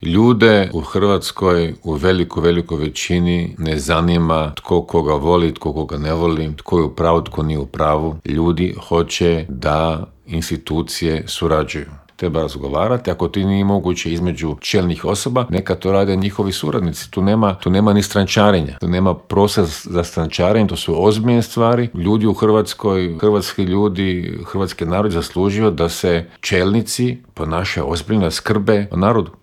ZAGREB - Bivši ministar vanjskih poslova Miro Kovač gostovao je u Intervjuu Media servisa u kojem se, među ostalim, osvrnuo na rezolucije o Ukrajini koju je Opća skupština Ujedinjenih naroda jučer donijela povodom treće obljetnice rata u toj zemlji.